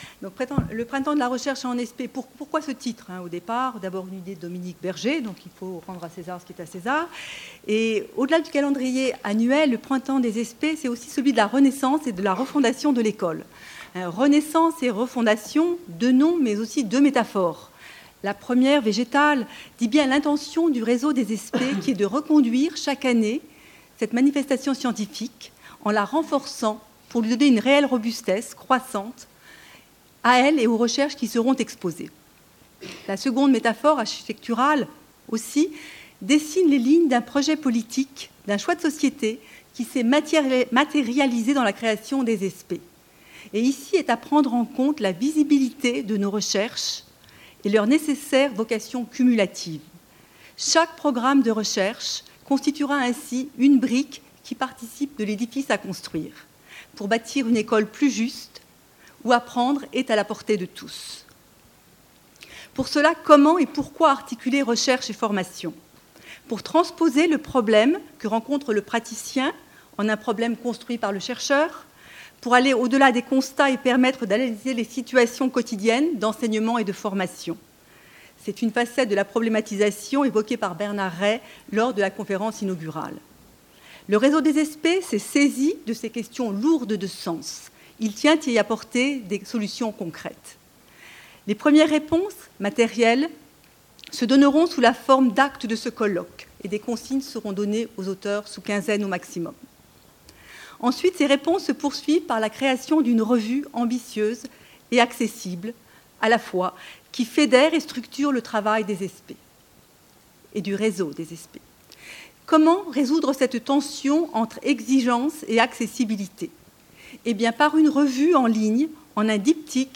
Tribune de clôture
Les discours de clôture